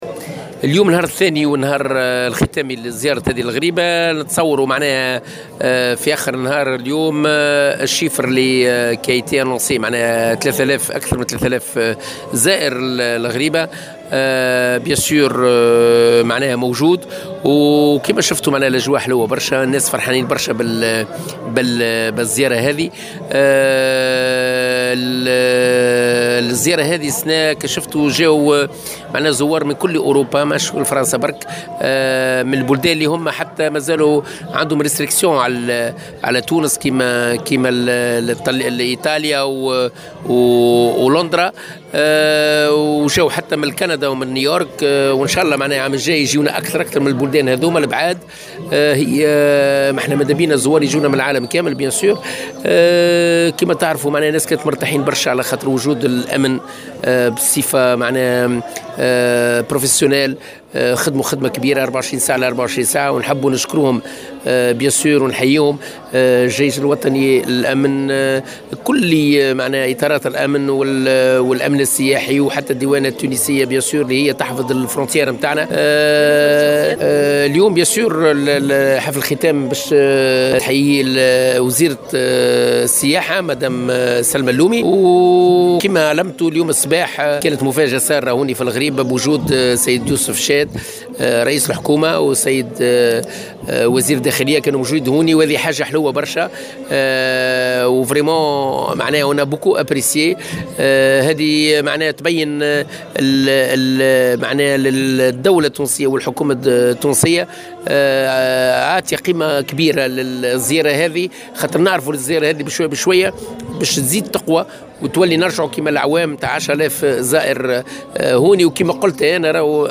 أكد روني الطرابلسي منظّم رحلات الغريبة في تصريح لمراسلة الجوهرة أف أم،على هامش اليوم الختامي لزيارة الغريبة اليوم الأحد أن عدد زوار الغريبة لهذا الموسم تجاوز3 آلاف زائر .